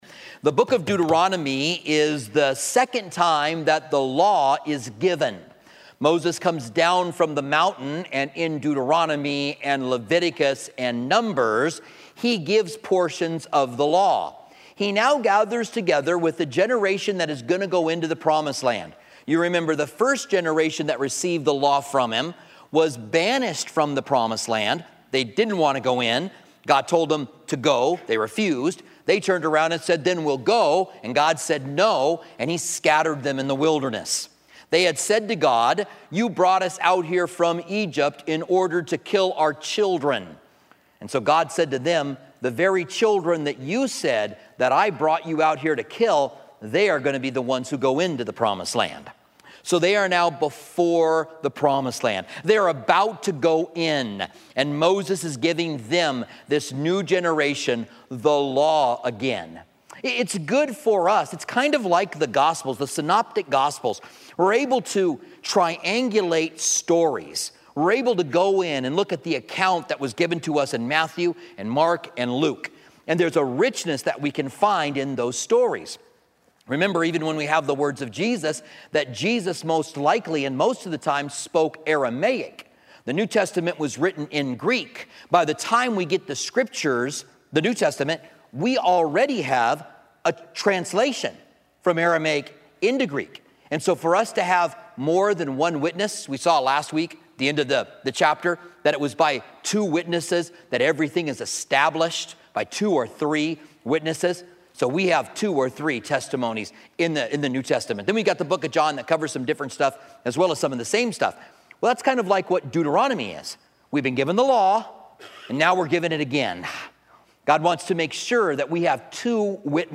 Commentary on Deuteronomy